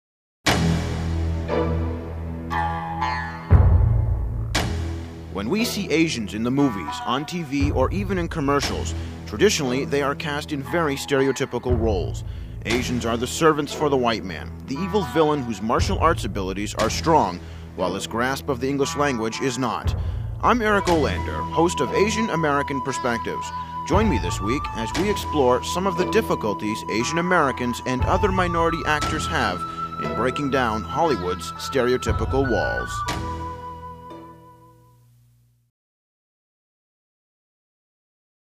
Each show was accompanied by a 30 second promotional spot that NPR affiliates could run during the week to invite listeners to tune in to Asian American Perspectives.
AA actors promo